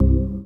ORGAN-19.wav